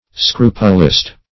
Scrupulist \Scru"pu*list\, n.